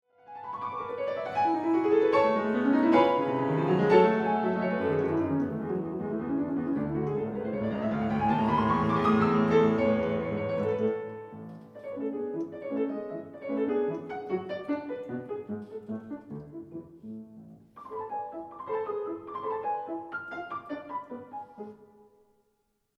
Let’s continue from where we ended the last clip and hear the second theme, which shows up at 0.11 :
Not too much to write home about perhaps…if it was not for the humorous quality: the first four notes…